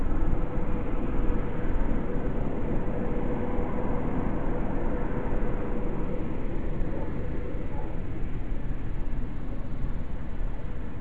2020 WILTON ENVIRONMENTAL NOISE